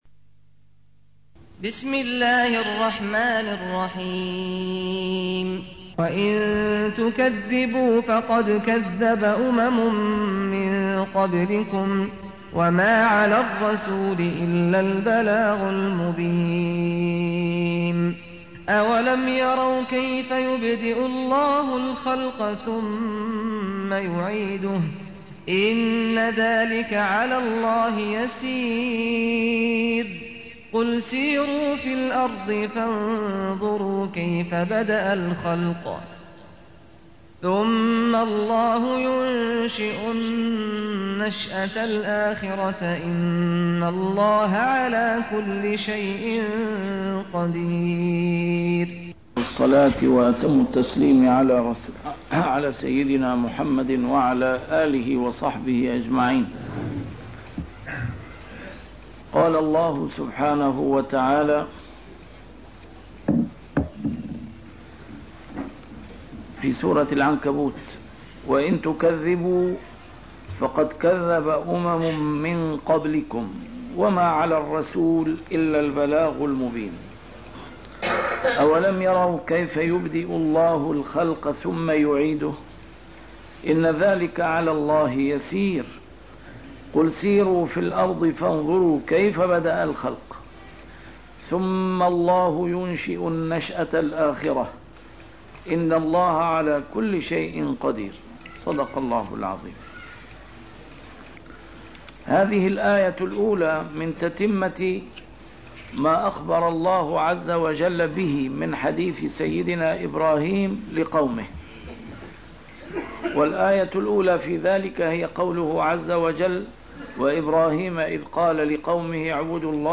A MARTYR SCHOLAR: IMAM MUHAMMAD SAEED RAMADAN AL-BOUTI - الدروس العلمية - تفسير القرآن الكريم - تسجيل قديم - الدرس 296: العنكبوت 18-19